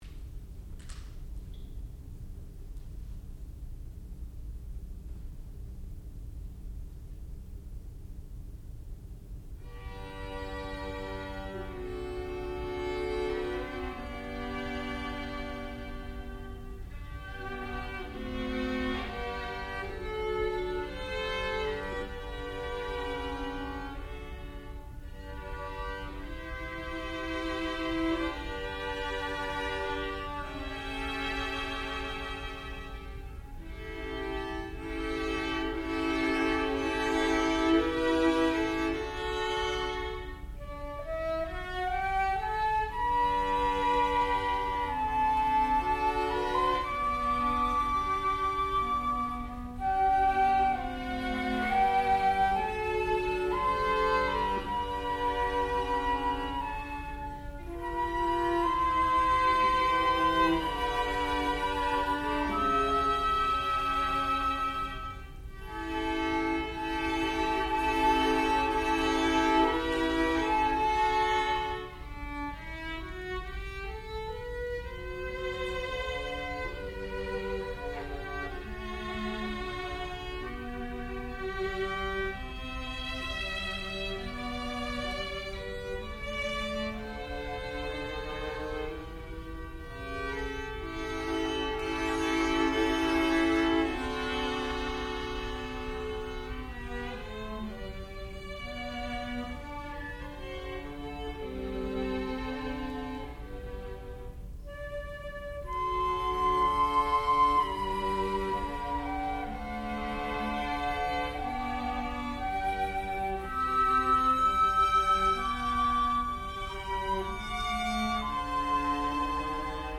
sound recording-musical
classical music
Advanced Degree Recital